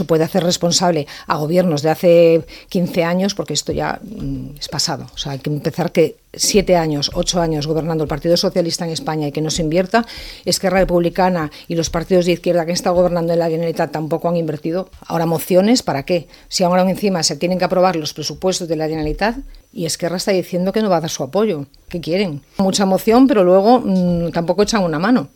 La portaveu del PP de Calella, Celine Coronil, s’ha definit com una usuària i patidora habitual de Rodalies a l’ENTREVISTA POLÍTICA de RCT i ha carregat contra la gestió dels últims governs del PSOE i ERC a l’Estat i a la Generalitat, respectivament, als quals responsabilitza del caos que va esclatar a principis d’aquest 2026 i afecta la línia R1.